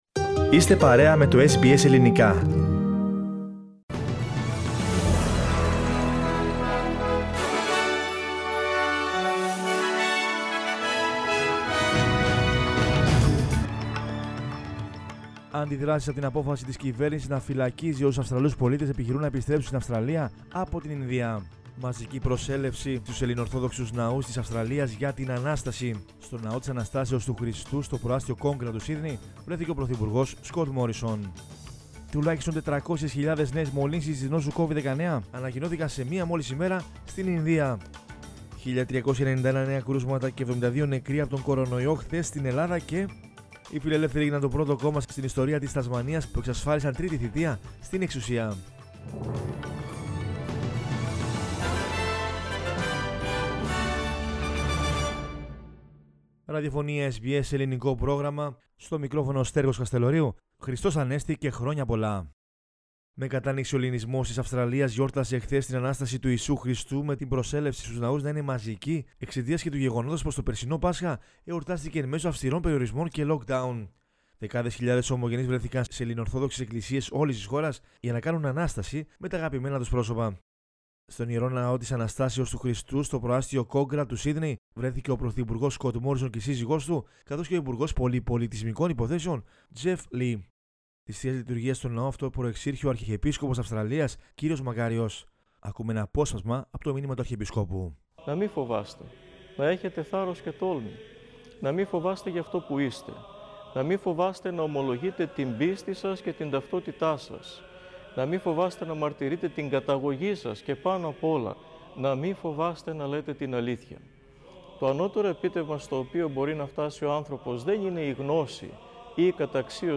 News in Greek from Australia, Greece, Cyprus and the world is the news bulletin of Sunday 2 May 2021.